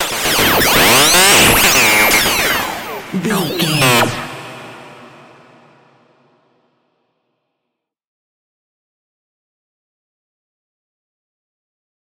In-crescendo
Thriller
Aeolian/Minor
scary
tension
ominous
dark
suspense
eerie
strings
synth
keyboards
ambience
pads
eletronic